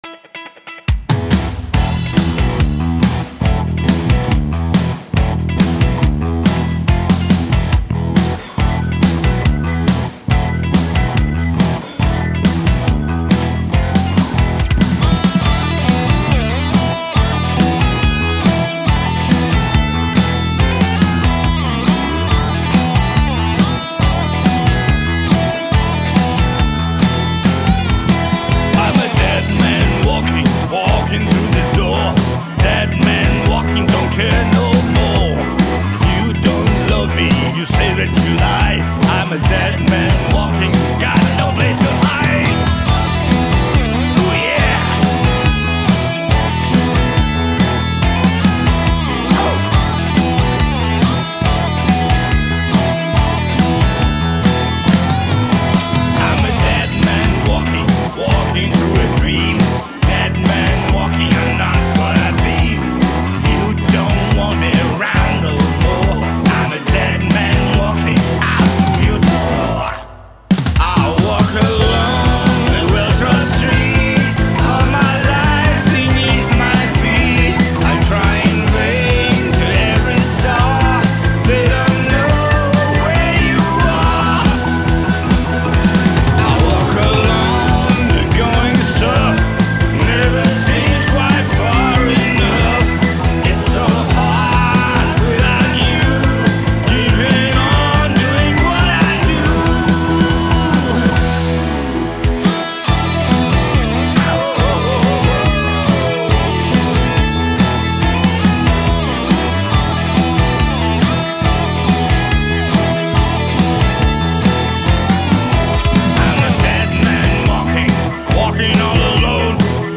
Темп ниже и в дропе.